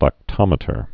(lăk-tŏmĭ-tər)